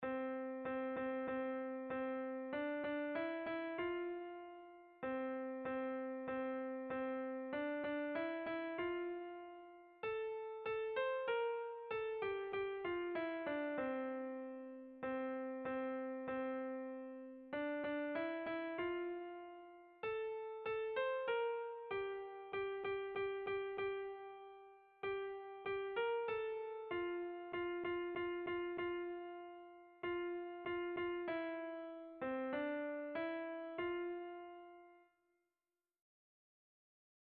Irrizkoa
ABA